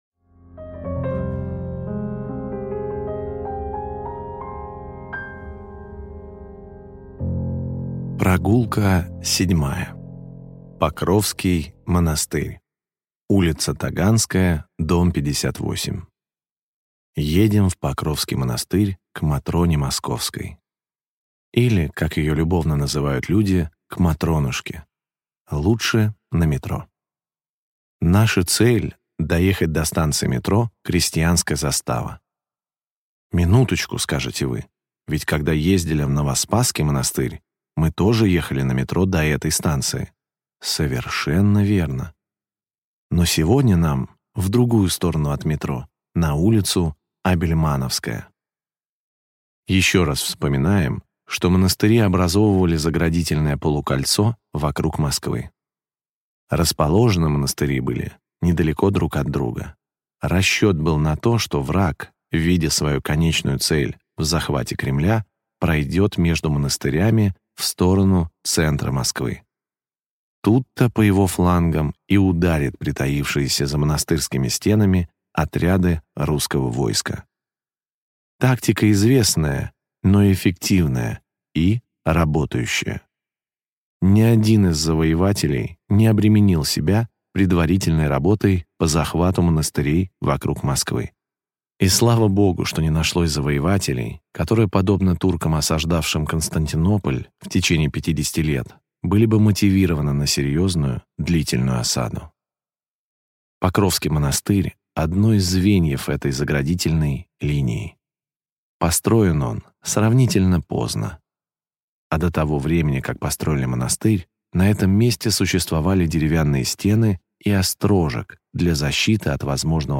Аудиокнига Монастырский пояс Москвы. Глава 7. Покровский монастырь | Библиотека аудиокниг